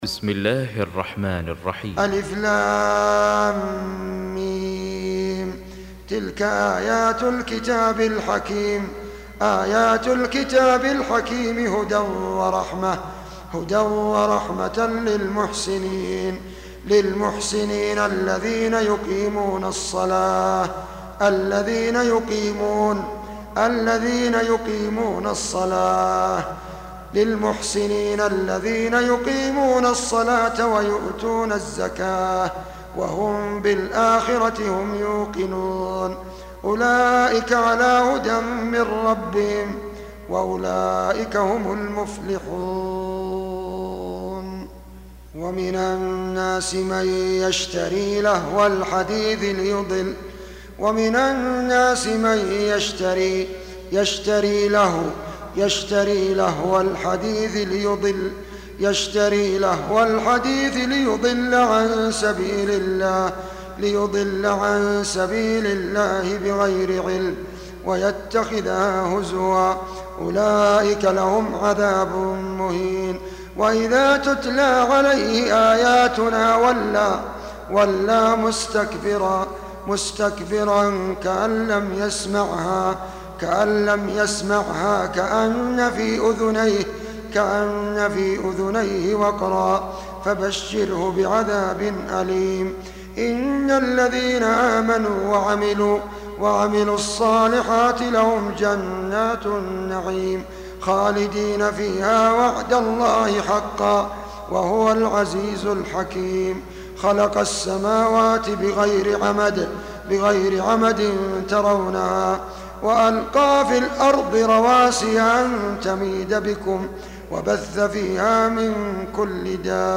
Surah Sequence تتابع السورة Download Surah حمّل السورة Reciting Murattalah Audio for 31. Surah Luqm�n سورة لقمان N.B *Surah Includes Al-Basmalah Reciters Sequents تتابع التلاوات Reciters Repeats تكرار التلاوات